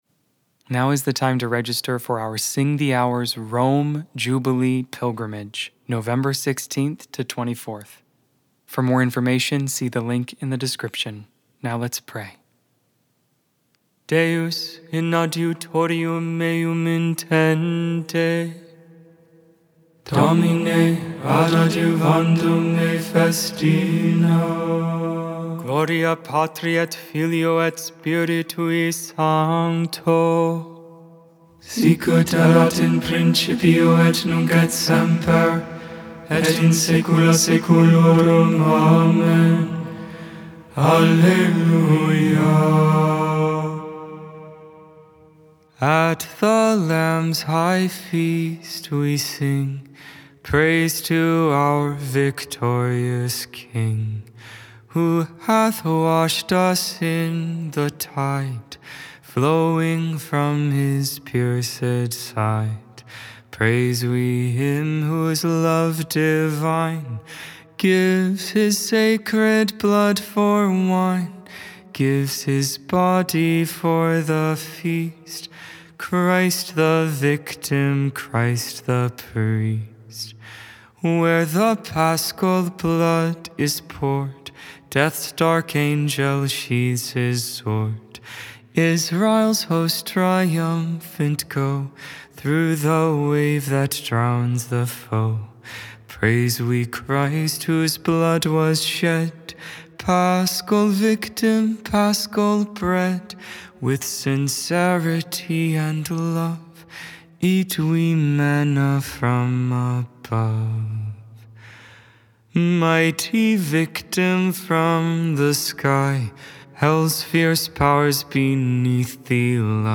Vespers, Evening Prayer for the 3rd Friday of Eastertide.